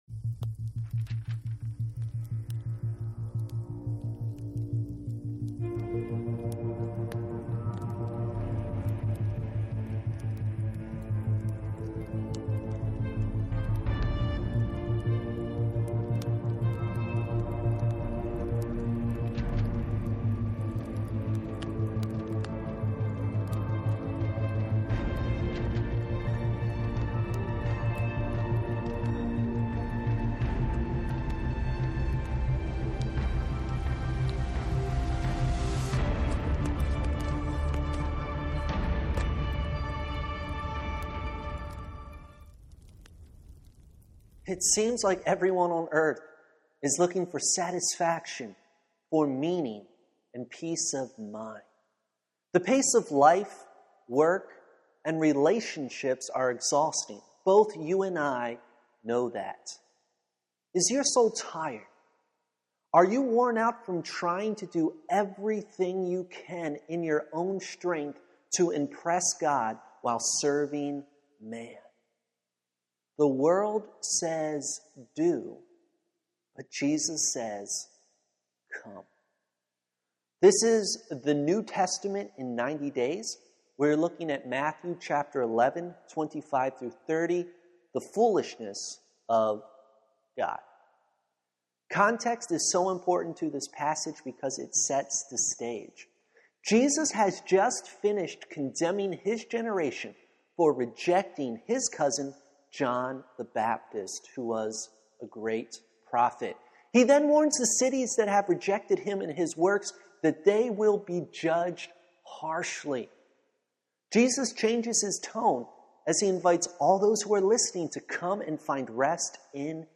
Bible Study – The New Testament In 90 Days Welcome to CMBC’s Bible studies.